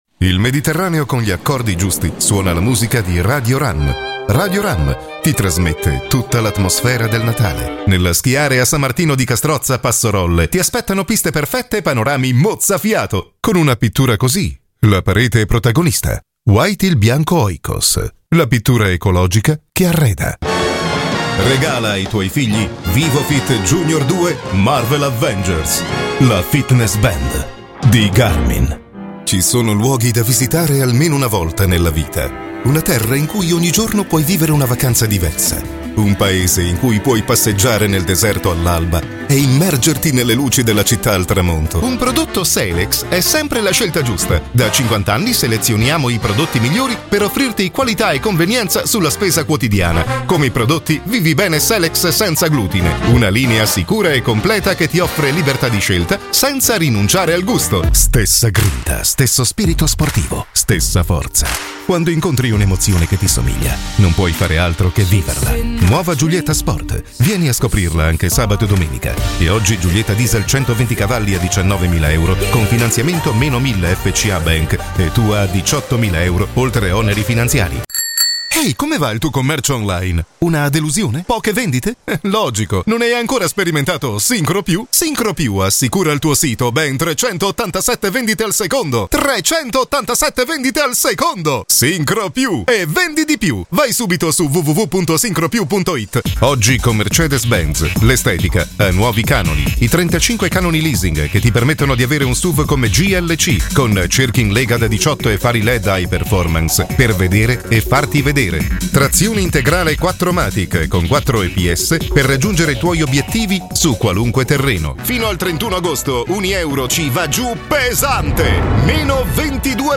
Italian native speakers with clear diction, without dialect influences, with a warm voice, but also powerful when necessary. Tone of middle-aged, quite deep.
Sprechprobe: Werbung (Muttersprache):
I can describe my voice as a "middle-aged" male voice, deep, warm, communicative, for documentaries, or where feeling is needed.
Or "powerful" and very dynamic for commercial applications.